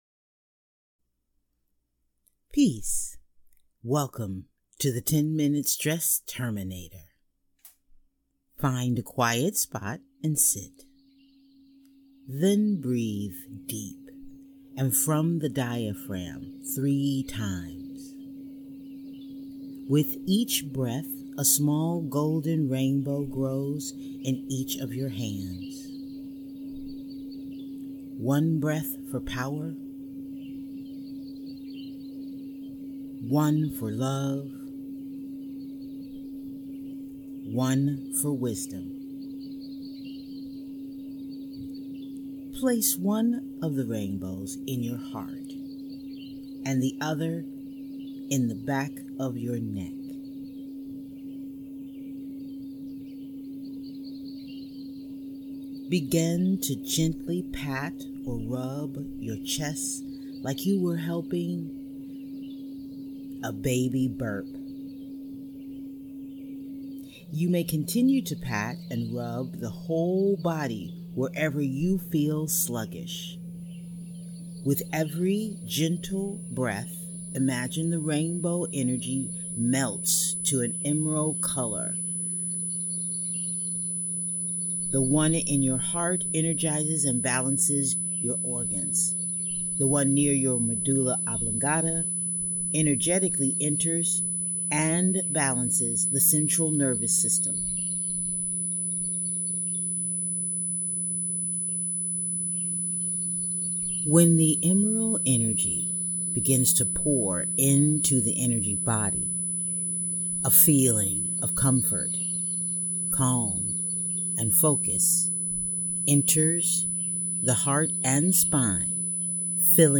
Listen to this 10-minute stress-busting healing tones meditation to bring relieve anxiety and bring calm.
[DAY 1] Stress Relief Guided Meditation
**The tones you hear are part of the audio and purposefully set in specific sound ranges.